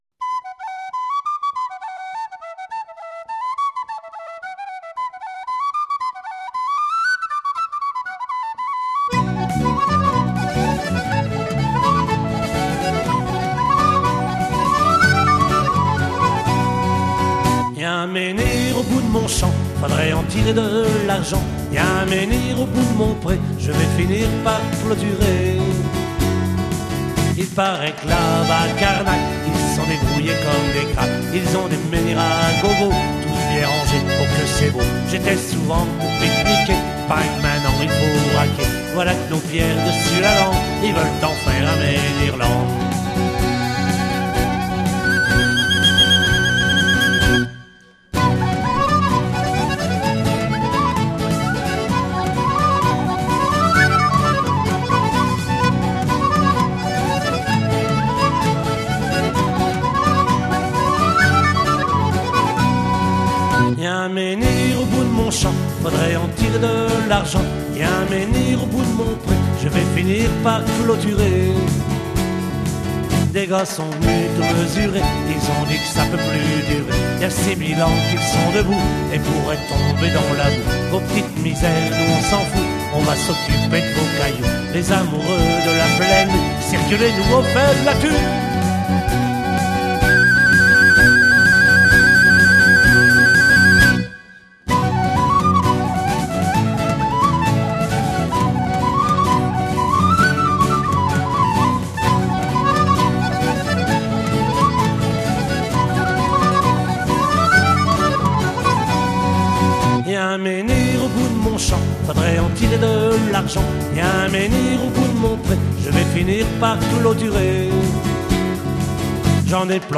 Maquette réalisée en Octobre 2005
aux studios du Micro-Bleu - RUCA (Côtes du Nord)
batterie
chant, guitare acoustique
guitare basse
whistles
accordéon diatonique